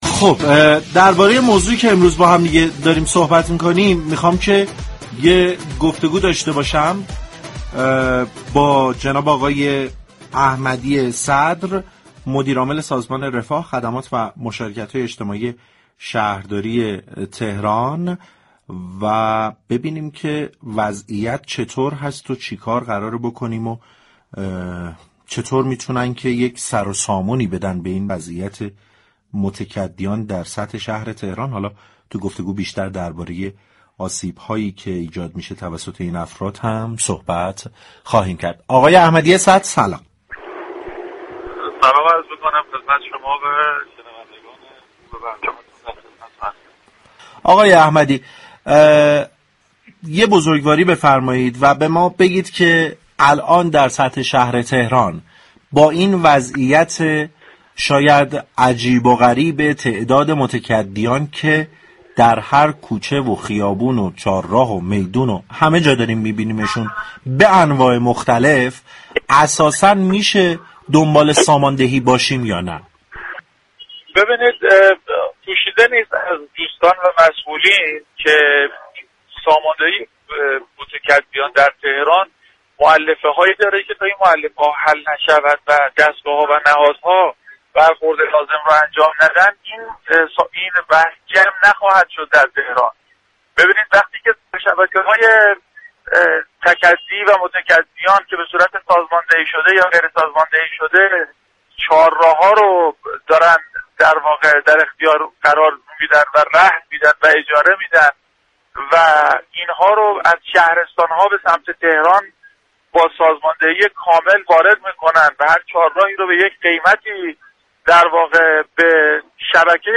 به گزارش پایگاه اطلاع رسانی رادیو تهران؛ برنامه «سعادت آباد» رادیو تهران در روز 13 اردیبهشت با موضوع ساماندهی متكدیان بر وروی آنتن رفت.